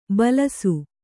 ♪ balasu